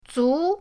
zu2.mp3